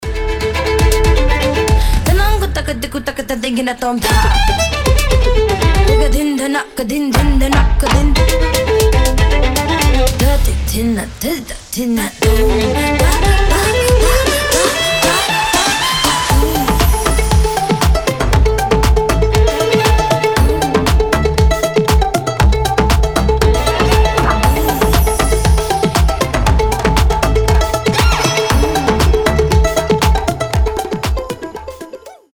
• Качество: 320, Stereo
восточные мотивы
женский голос
скрипка
Dance Pop
инструментальные
Indie Dance